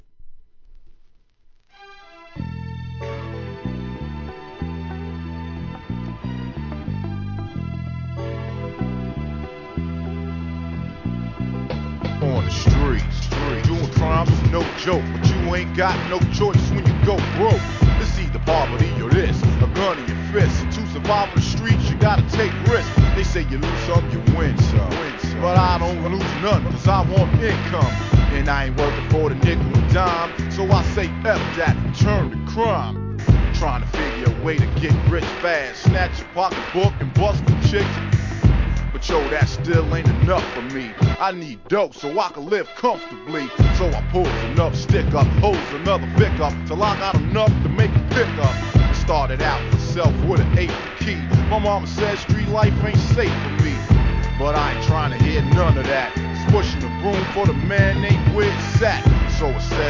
HIP HOP/R&B
1994年、JAZZYなピアノLOOP上でスムースRAP!!